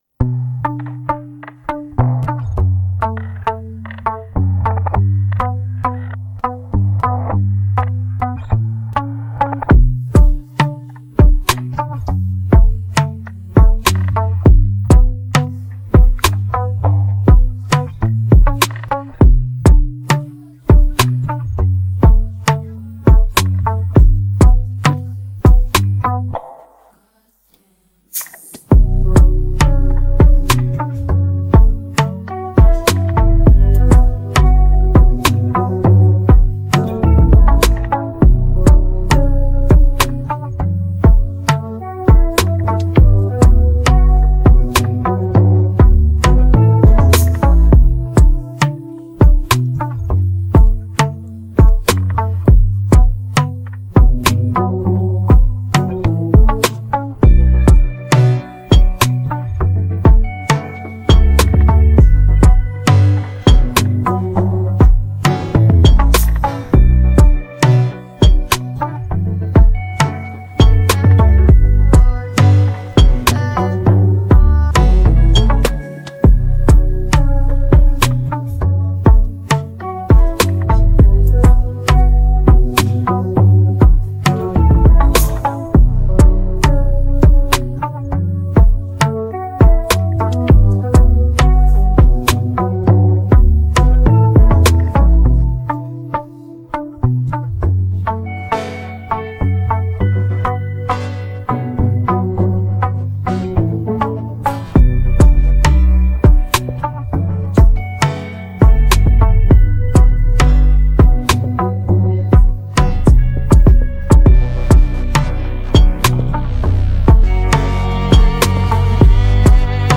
Afro-R&B Fusion